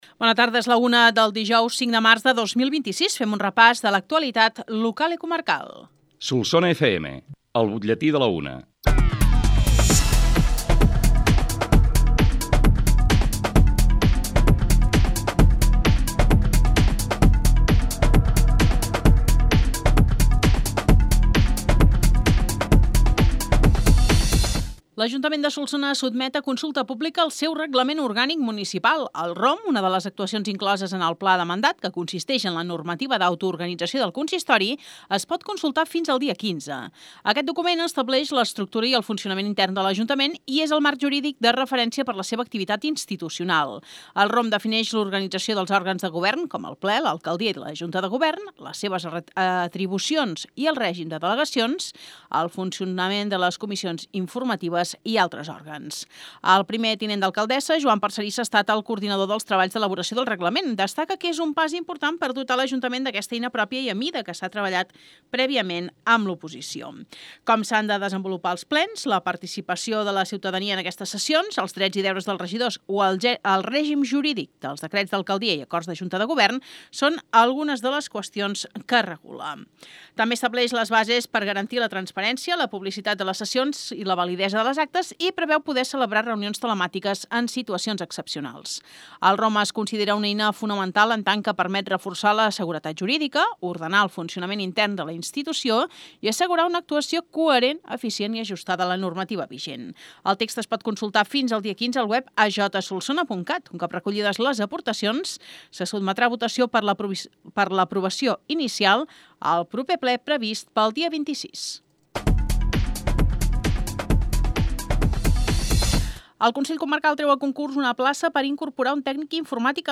L’ÚLTIM BUTLLETÍ
BUTLLETI-5-MARC-26.mp3